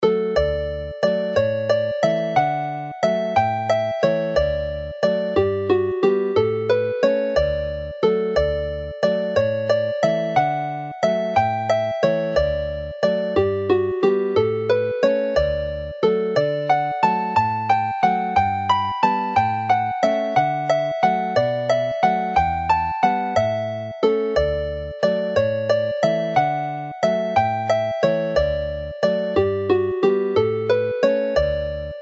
Chwarae'r alaw'n araf
Play the melody slowly